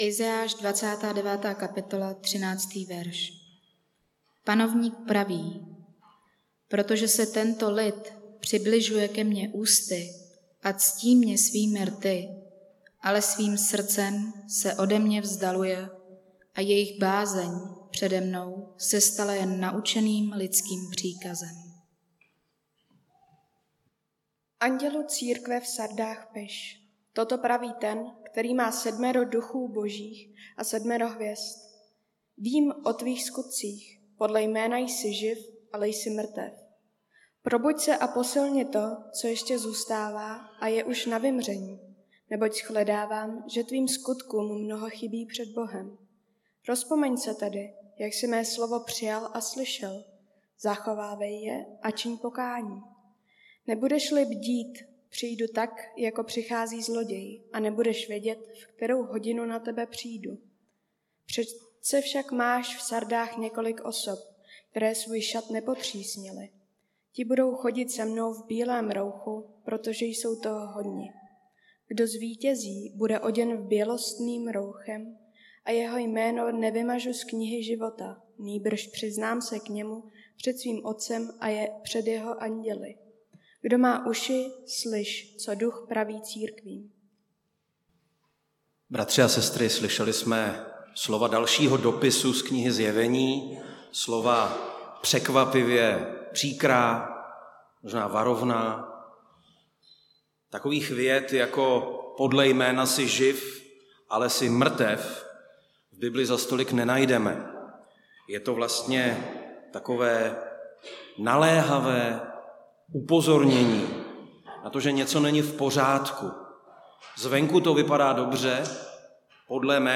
Nedělení kázání – 12.6.2022 List do Sard